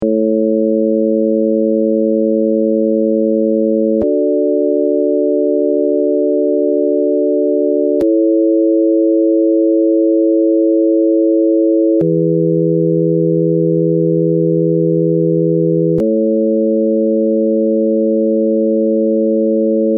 純正律カデンツァ
４声の合成は、電子的に２声+２声とし音響的に４声に合成する方法とする。
純度 クリアな音 ややざわついた音
jt_cadenza.mp3